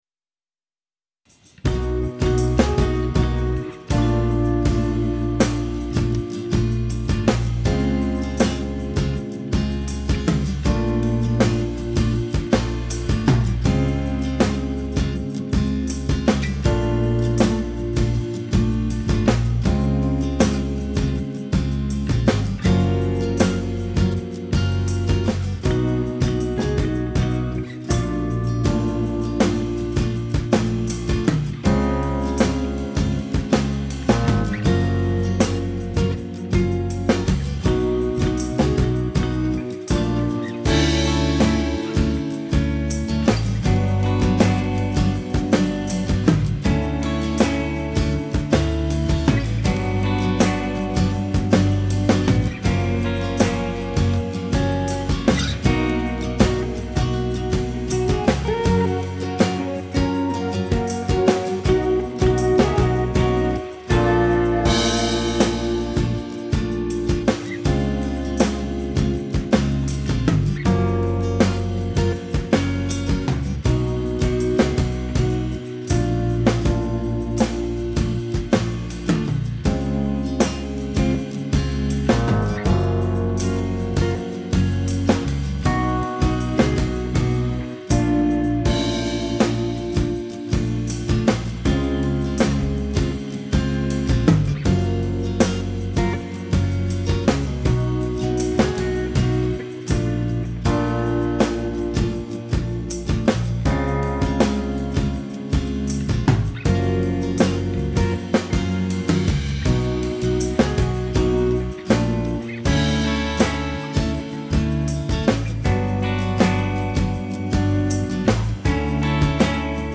Hey Here's a song in progress, needs some cool female jazzy vocals. There's a few mistakes in the mix (lack of fade out-bass heavy? drums are midi kit : () and a few inacuraccies in the playing but overall I think it's grooving pretty good.